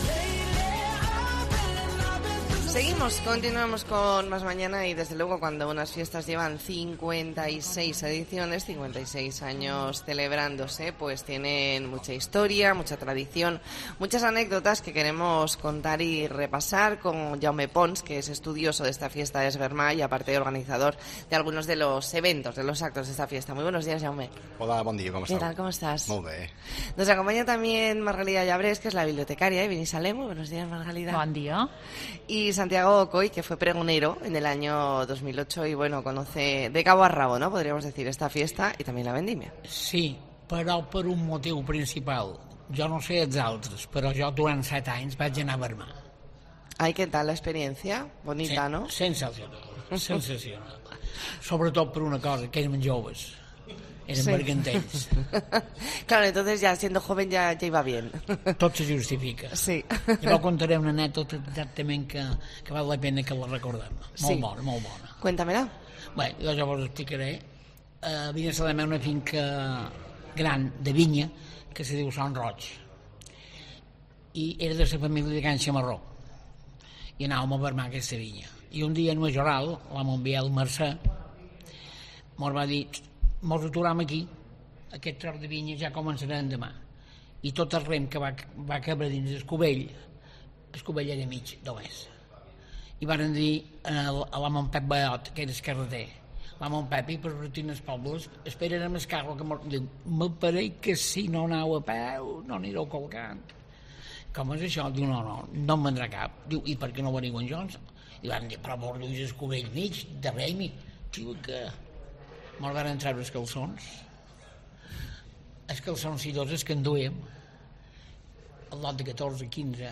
Entrevista en La Mañana en COPE Más Mallorca, jueves 22 de septiembre de 2022.